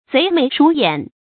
賊眉鼠眼 注音： ㄗㄟˊ ㄇㄟˊ ㄕㄨˇ ㄧㄢˇ 讀音讀法： 意思解釋： 形容神情鬼鬼祟祟。